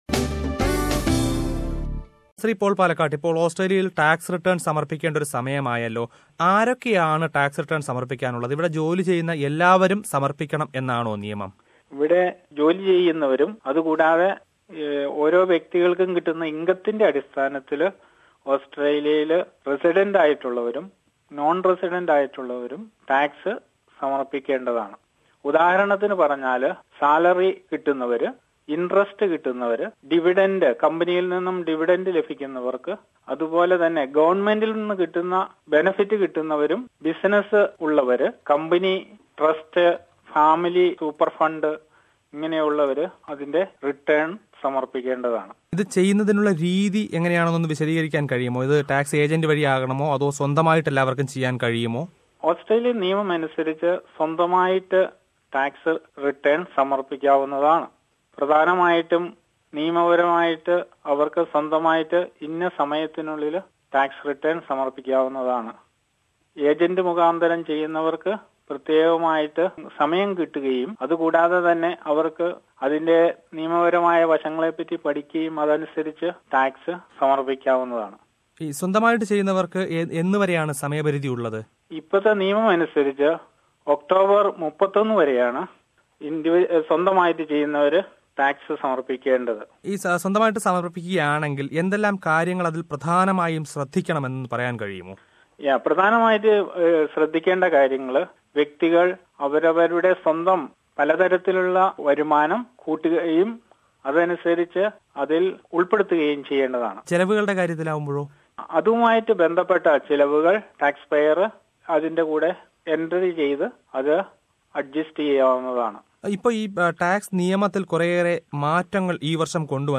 Listen to the first part of an interview